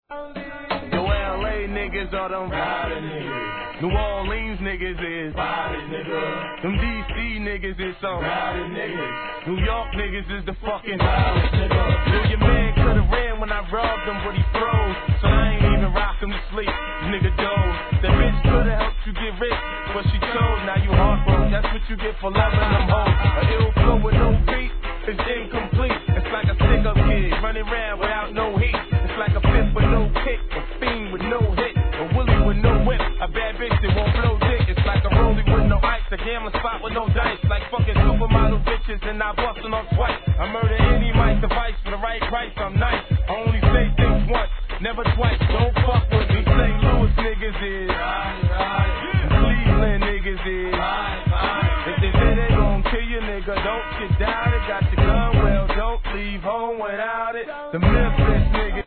HIGHクオリティーなHIP HOP x REGGAEブレンド・シリーズ!!